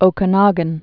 (ōkə-nŏgən)